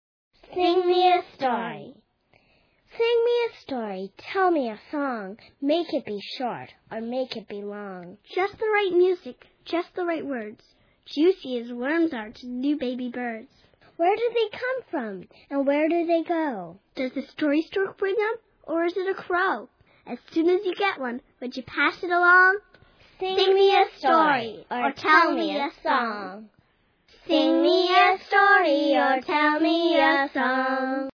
It's a collection of poems performed by kids, but For Kids By Kids is good for everybody big and little. Listeners will grin from ear to ear over the mispronounced words, the imaginative language and the expressiveness of each kid's unique voice.